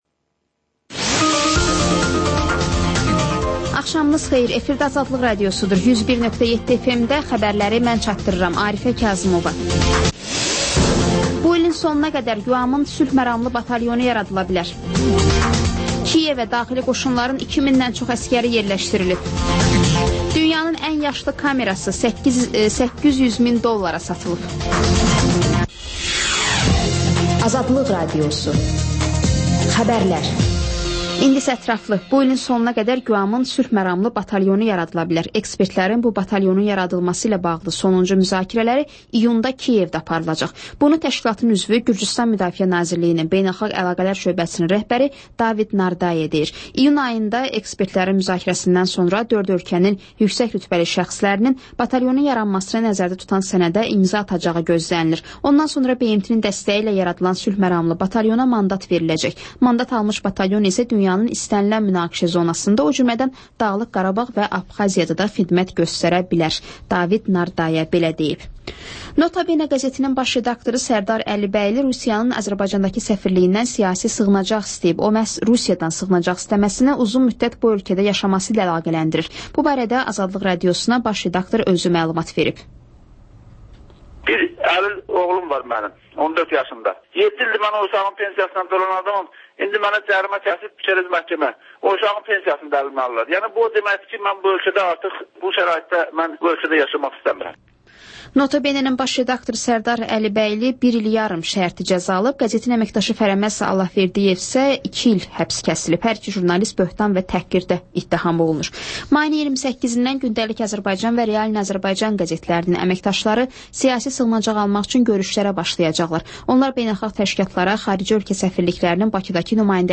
Xəbərlər, ardınca PANORAMA verilişi: Həftənin aktual mövzusunun müzakirəsi.